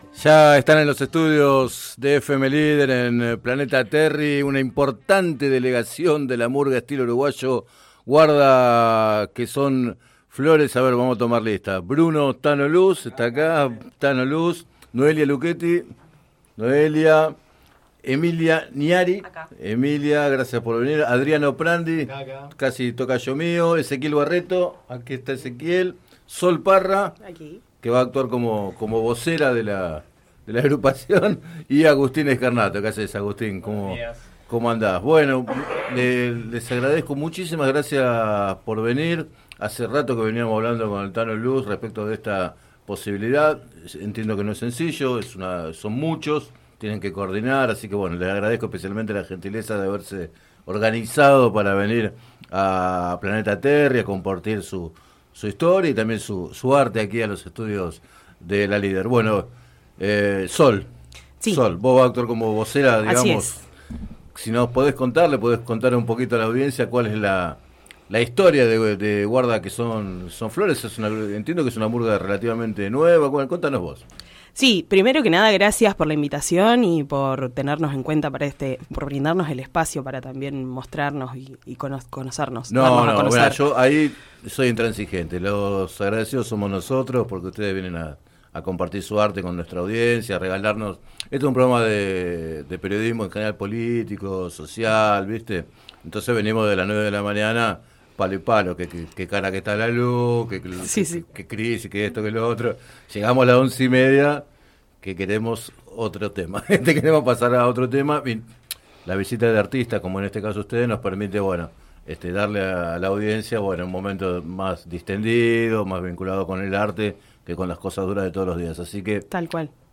Siete integrantes de la agrupación surgida en el Centro Cultural Artigas compartieron su música con la audiencia de Líder y contaron los secretos del estilo uruguayo y del origen de su nombre.